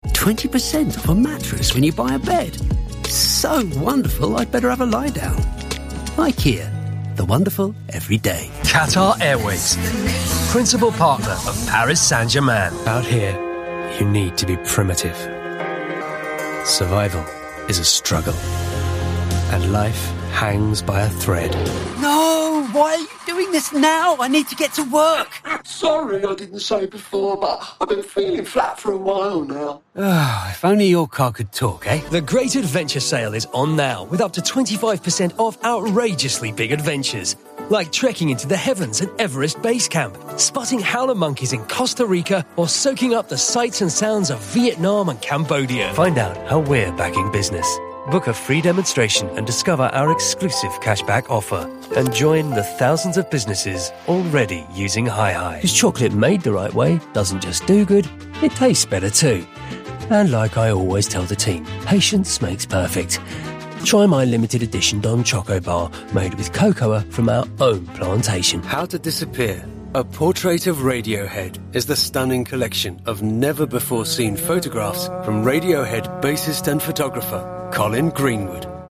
Commercial Showreel
Male
Estuary English
Neutral British
British RP
Confident
Cool
Friendly
Authoritative